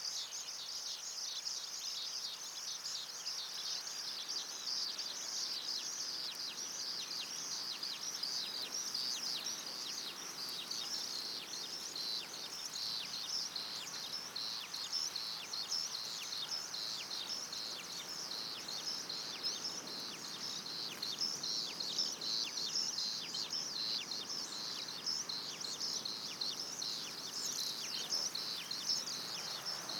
♫145.秀姑巒溪旁西瓜田 - 花蓮影視基地
此處瓜田位於中央山脈與海岸山脈之間，瓜田旁即是秀姑巒溪，景色優美，廣闊且人車少，適合拍攝農田故事。
西瓜田環境音.mp3